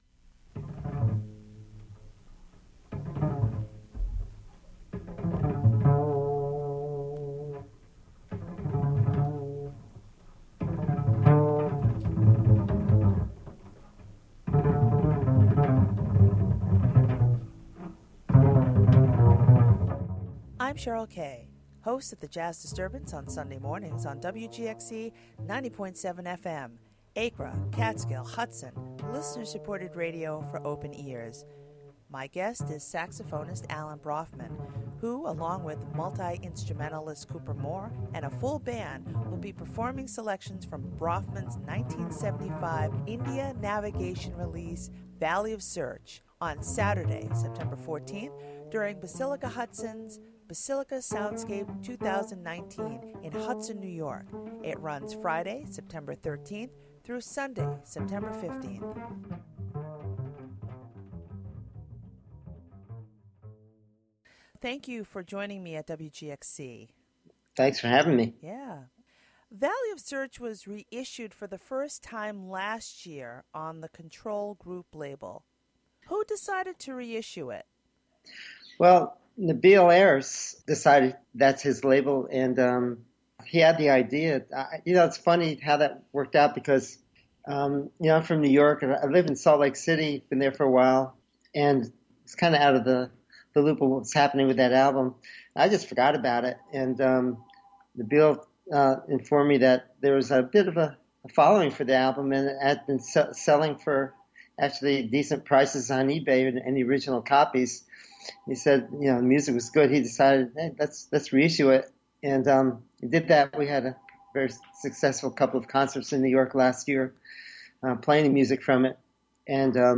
Interviewed
Recorded in the WGXC Hudson Studio, Thu., Sep. 12.